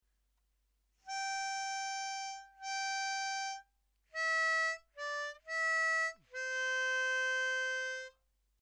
It’s a Sea Shanty sung by Sailors and Pirates.
We have chopped the tune up into small chunks to help you.